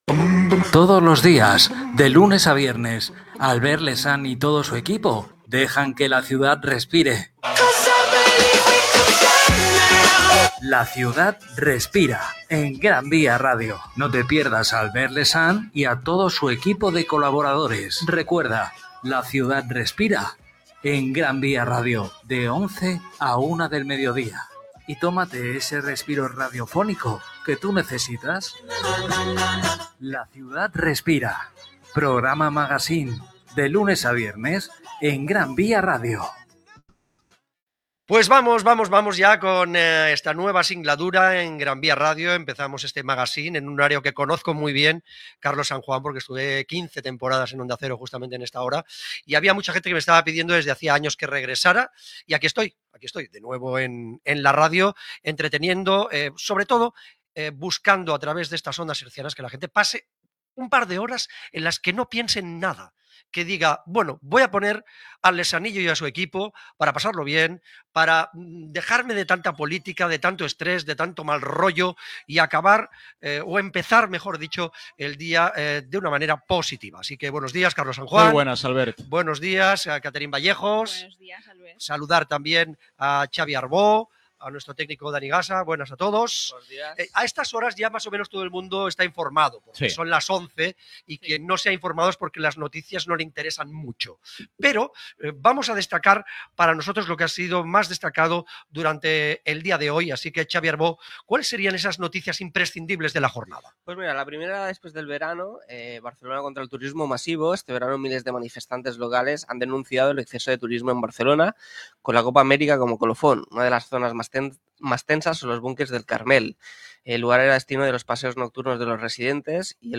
Careta del programa, presentació de la primera edició del programa i els noms de l'equip. El turisme massiu a Barcelona i els preus dels pisos de lloguer
Entreteniment